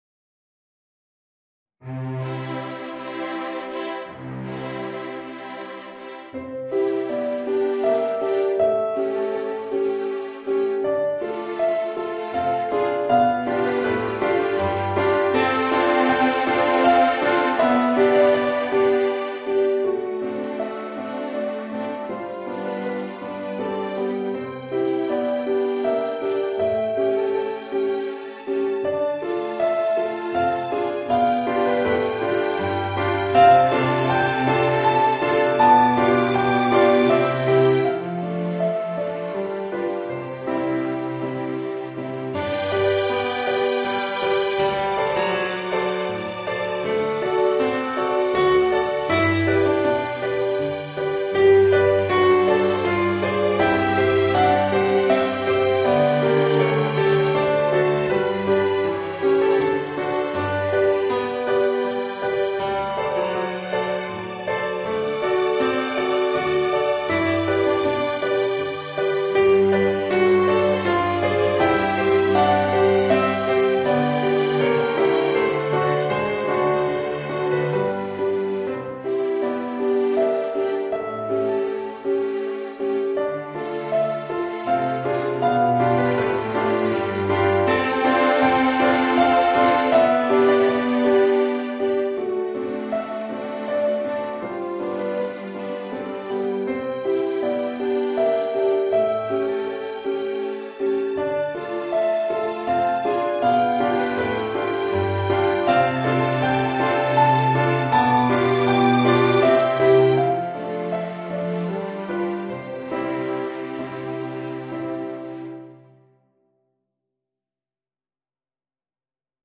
Voicing: Piano and String Quartet